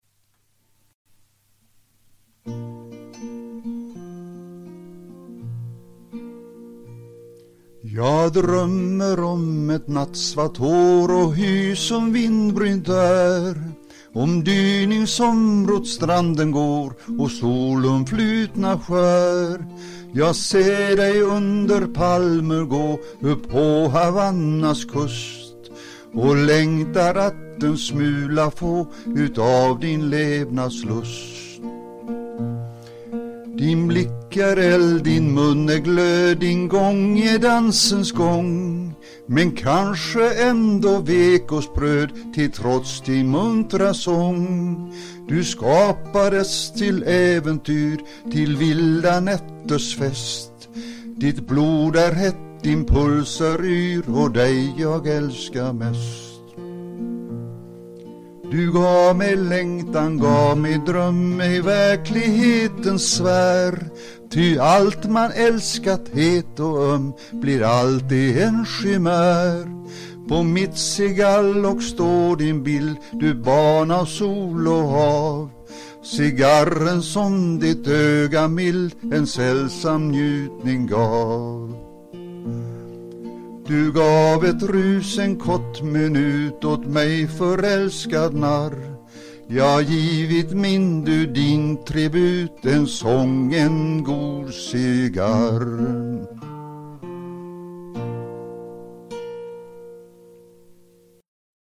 sjunger sin egen nya tonsättning av en dikt